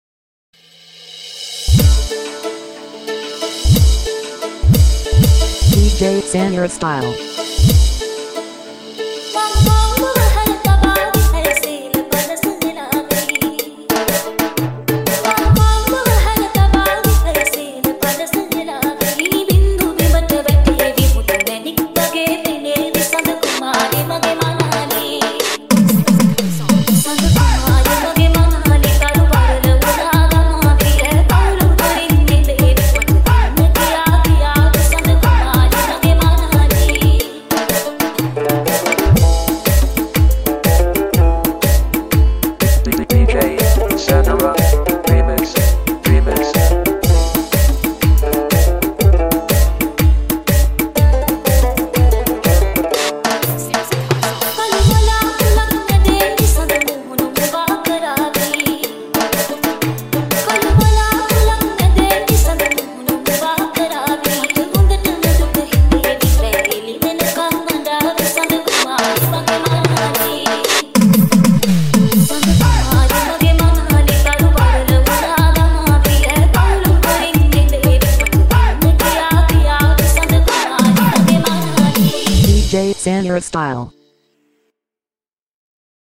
Acoustic Thabla Dj Remix